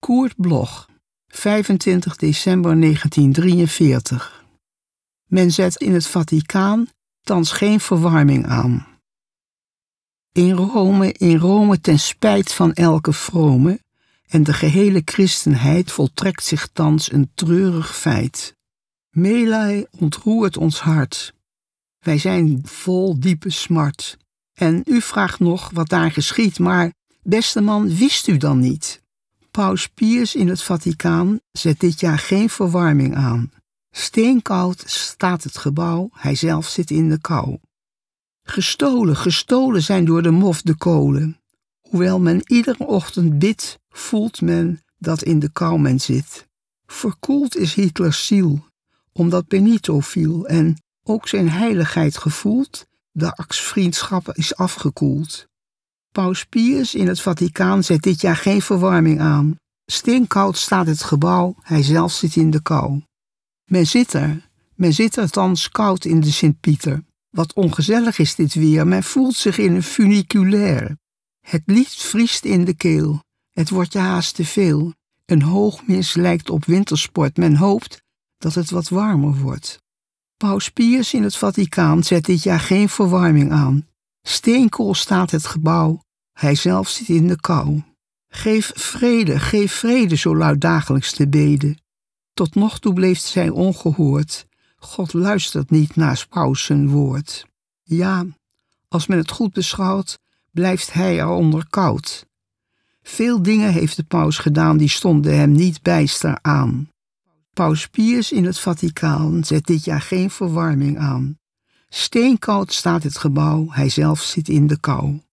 Recording: MOST, Amsterdam · Editing: Kristen & Schmidt, Wiesbaden